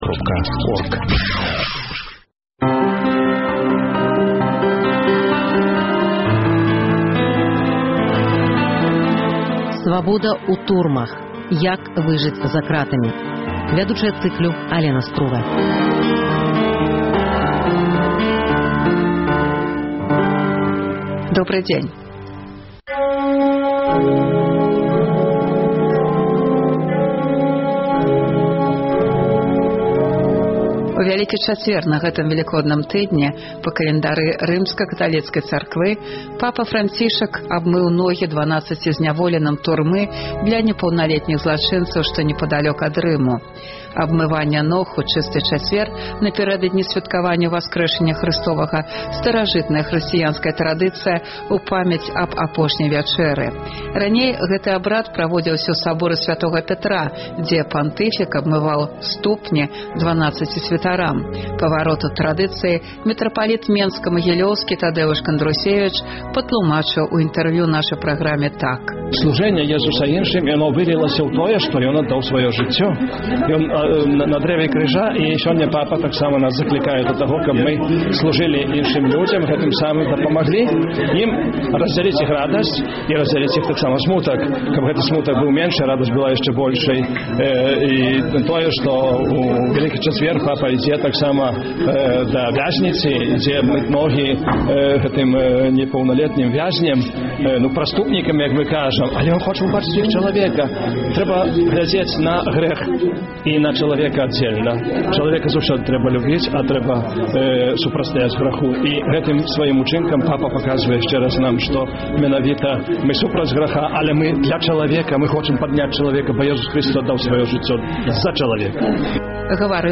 Стаміўшыся змагацца за сваіх блізкіх, сямейнікі зьвяртаюцца да ўчастковых з просьбай накіраваць родных у ЛПП – установу сыстэмы МУС, мэта якой "адвадзіць" чалавека ад алькаголю з дапамогай працоўнай тэрапіі. Эфэктыўнасьць лячэбна-працоўных прафілякторыяў абмяркоўваюць яго былыя сядзельцы, журналіст, псыхоляг, мінакі на вуліцах Гомеля.